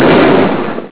Boom.wav